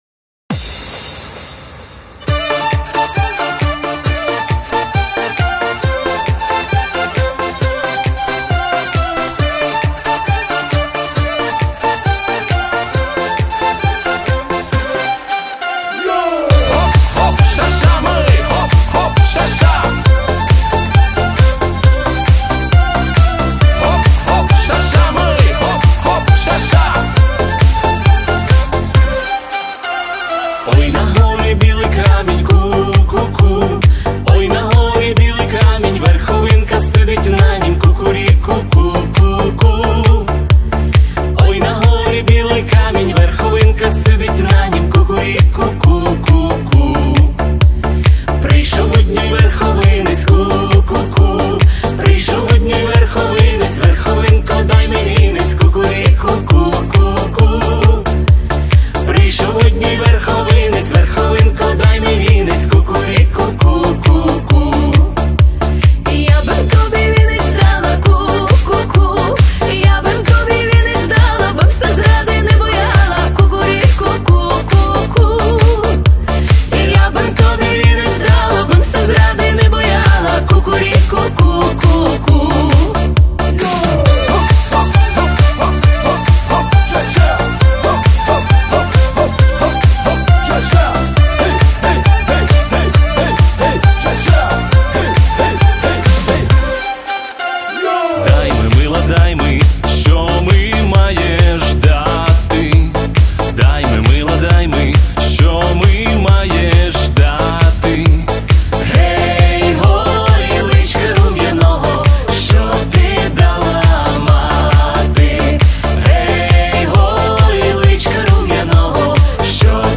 ЗАПАЛЬНИЙ УКРАЇНСЬКИЙ МІКС